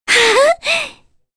Kirze-vox_pur2_kr.wav